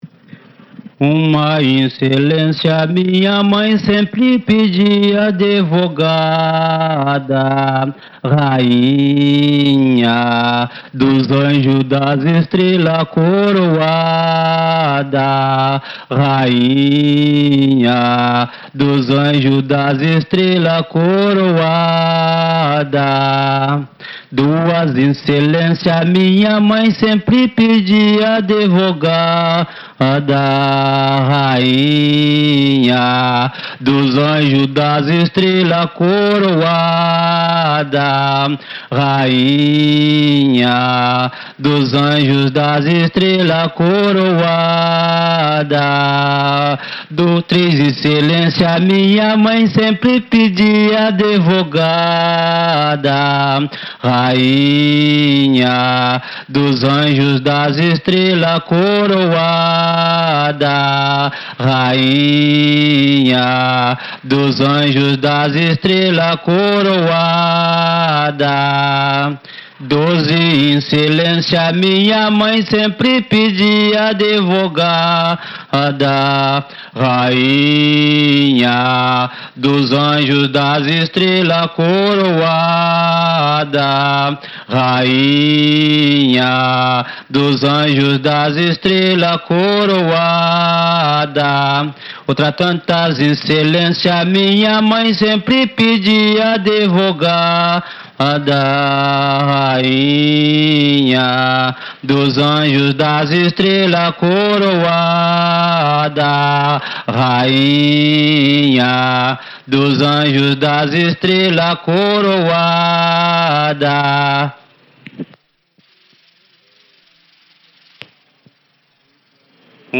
Incelências (várias), toadas de folia de reis e pontos de Jongo (vários) colhidos em 1963.
Também inclui a gravação de uma breve entrevista com o informante. Na sequência, há uma série de outras incelências gravadas em Manguinhos também em 1963.